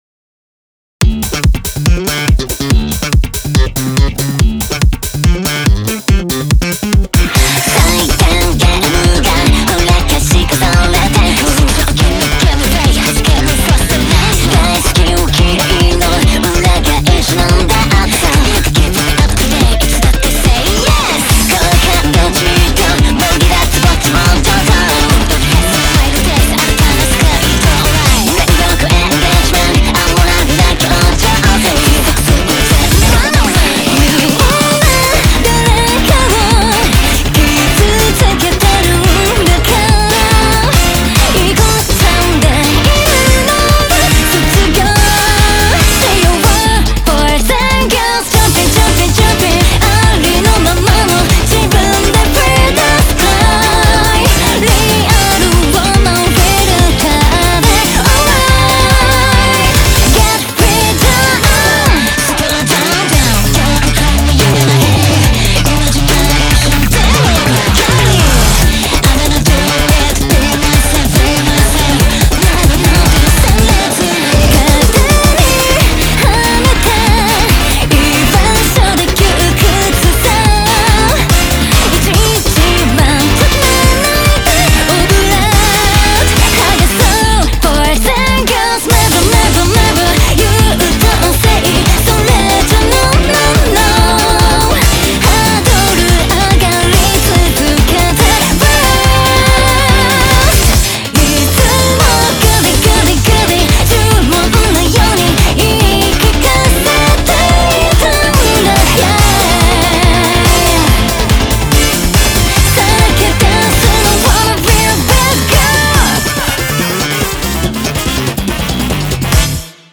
BPM142
Audio QualityPerfect (High Quality)
Genre: FUNK POP.